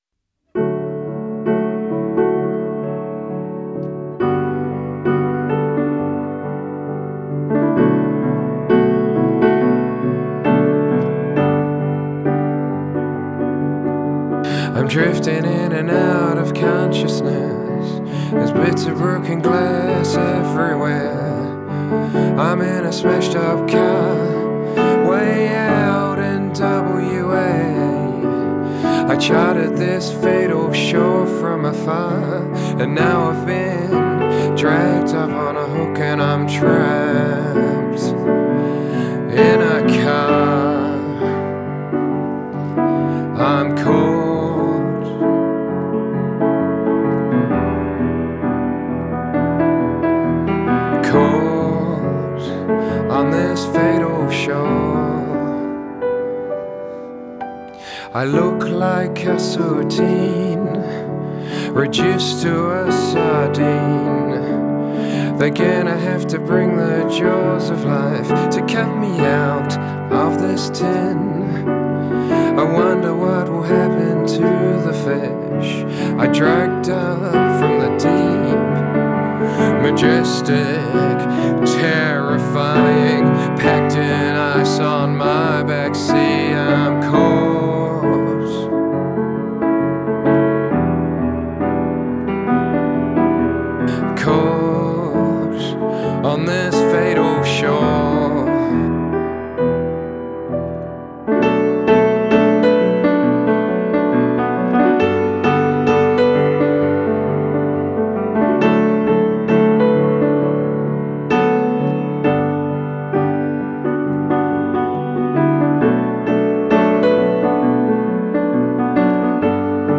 Melbourne based band